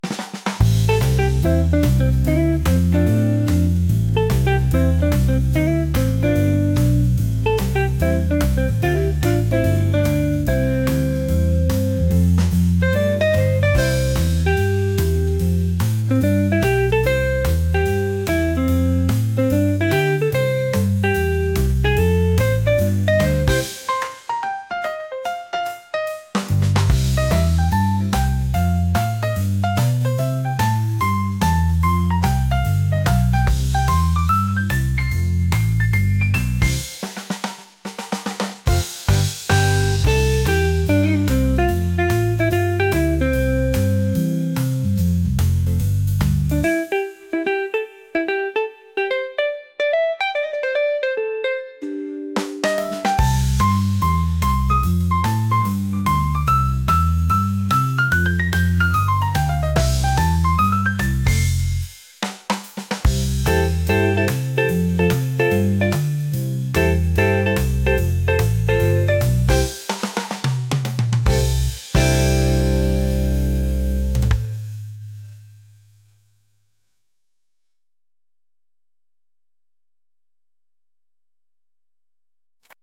upbeat | soul | jazz | groovy